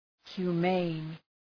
Προφορά
{hju:’meın}